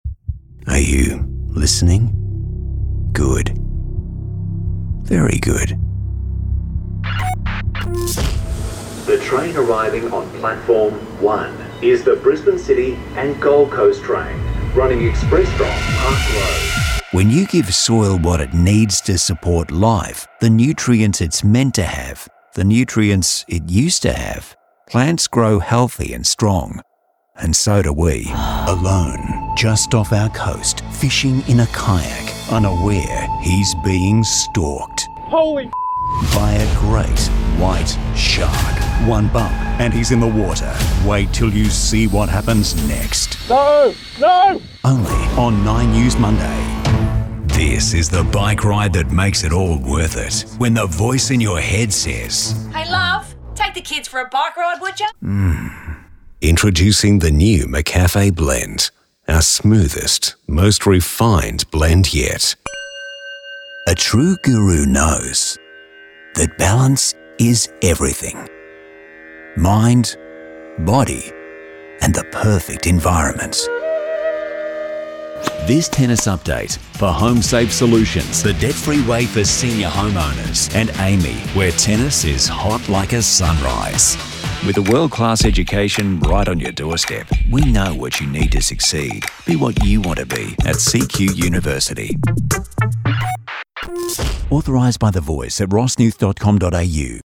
Australia Voice Artist
Commercial
Australian, Neutral
Young Adult
Middle Aged